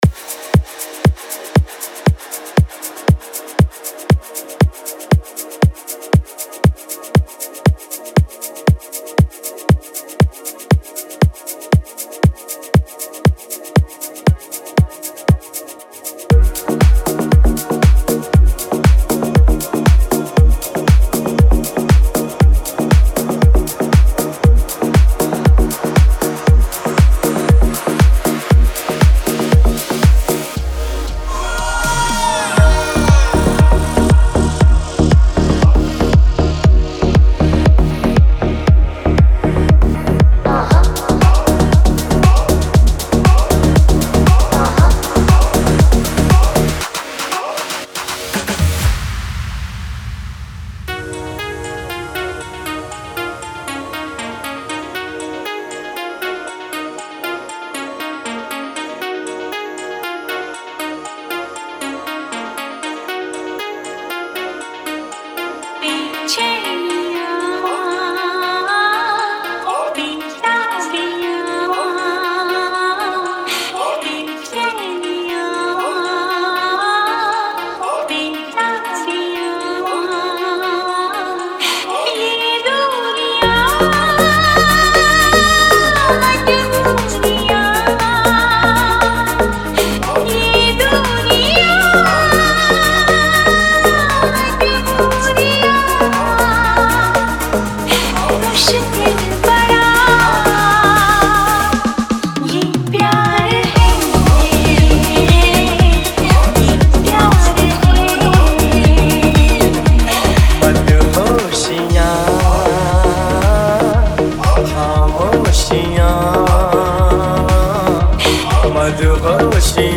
Bollywood Deep House
Bollywood DJ Remix Songs